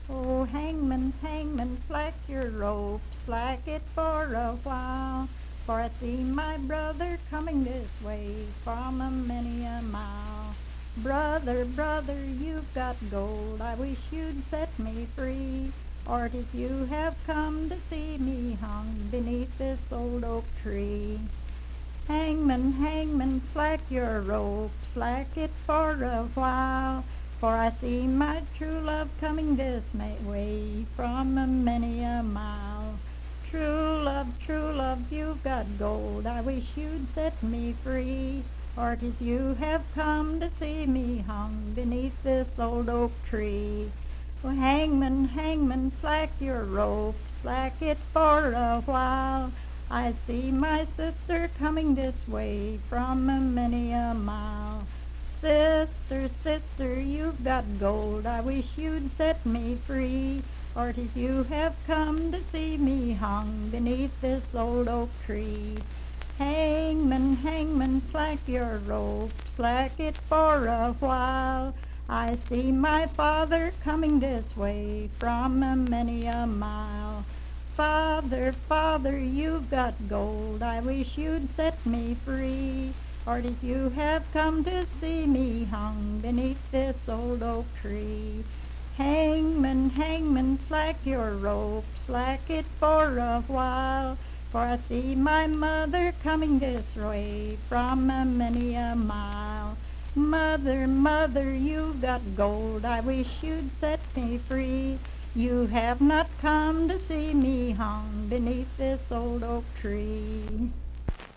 Unaccompanied vocal music
Verse-refrain. 10(4w/R).
Voice (sung)
Hardy County (W. Va.), Moorefield (W. Va.)